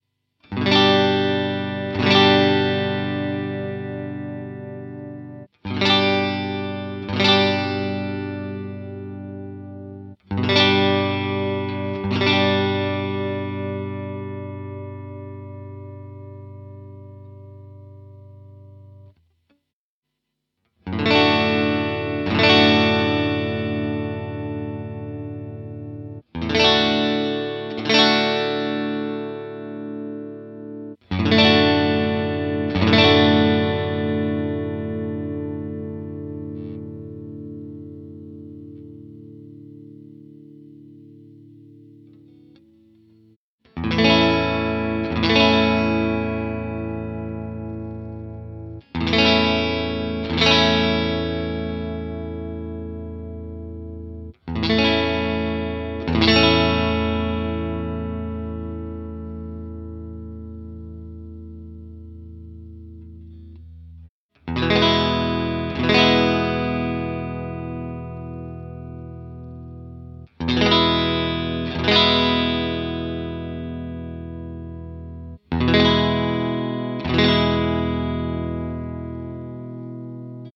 These are pretty boring examples, I did just strumm some chords. Each chord is strummed twice, then the guitar changes. All samples are done with the same cable and for sure its the same amp, because I recorded without amp with a Line6 Pod Xt and the simulation is done on the PC by the Line6 Soundfarm plugin.
So, always first is the Squier CV, then the G&L Legacy and at last the Hohner with the Fender Texas Specials.